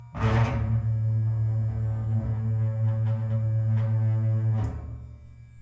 剃刀，打击乐器组 " 剃刀光滑3
描述：电动剃须刀，金属棒，低音弦和金属罐。
Tag: 剃须刀 重复 电机 金属 金属加工 发动机